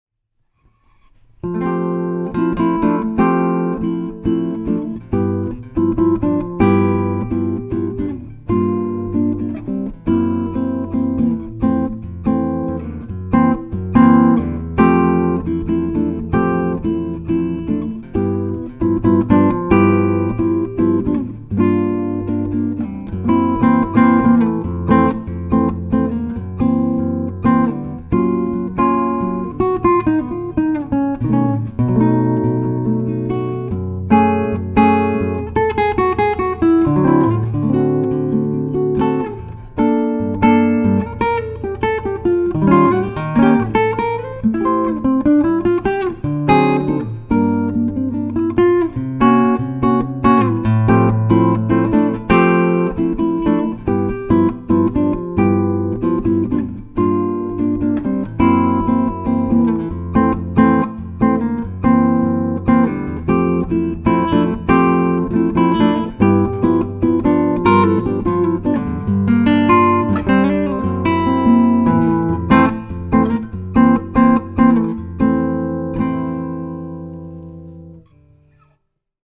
Recorded on Macassar Ebony Buscarino Grand Cabaret, Comp.